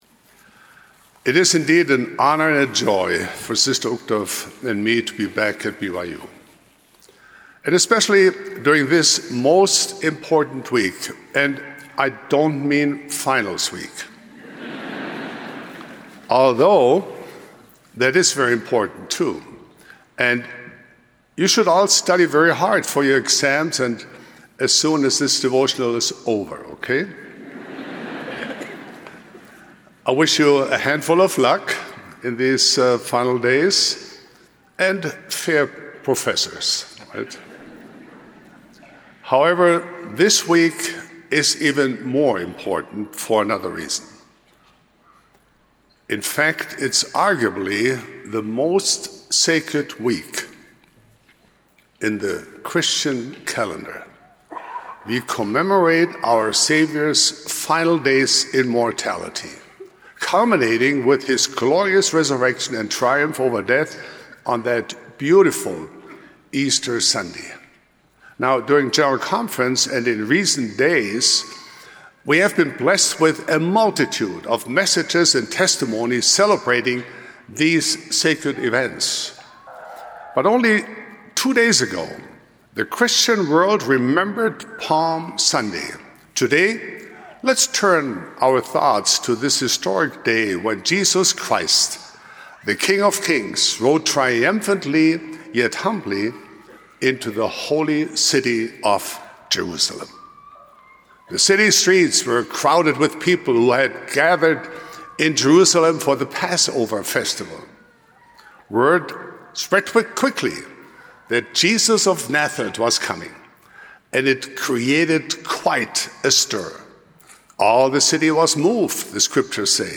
Audio recording of Joyfully Receive the Unexpected Messiah by Dieter F. Uchtdorf
of the Quorum of the Twelve Apostles